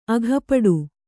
♪ aghapaḍu